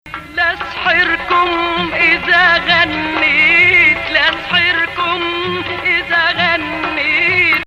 Jins Mukhalif